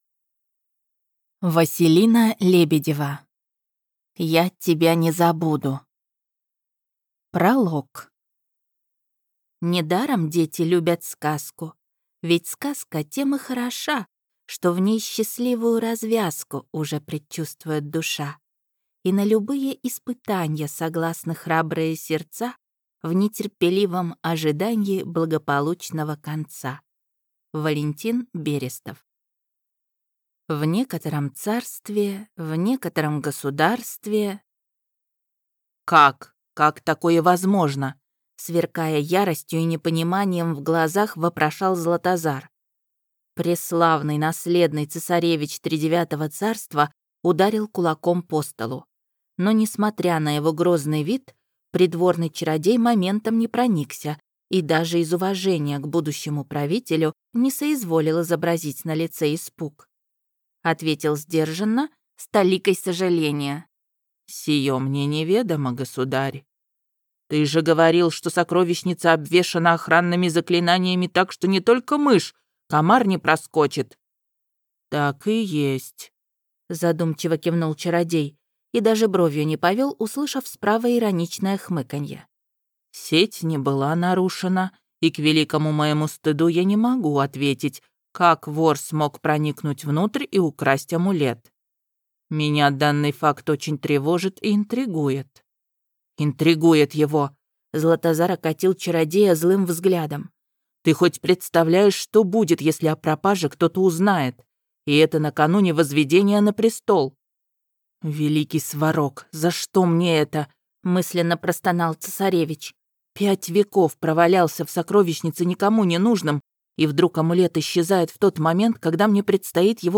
Аудиокнига Тридевятое счастье | Библиотека аудиокниг